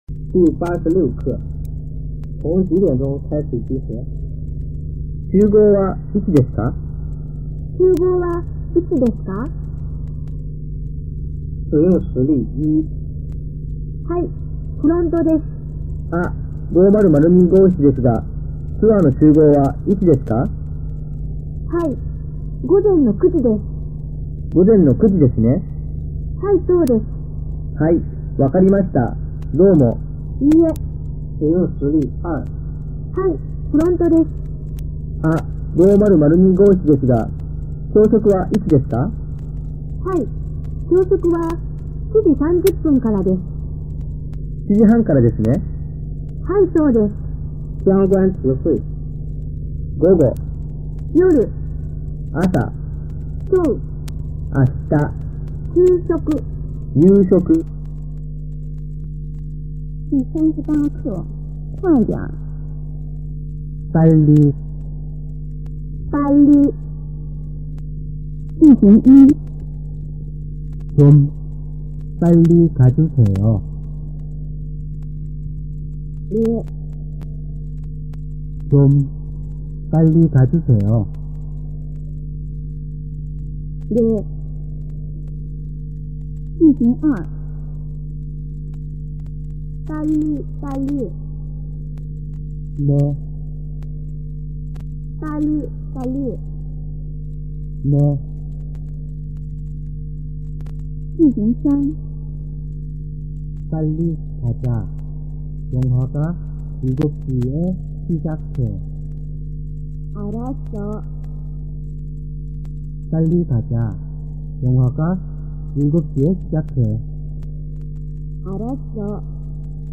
Khả năng đàm thoại của các bạn sẽ lưu loát hơn và chuẩn xác hơn khi kết hợp với băng cassette do người Hàn đọc.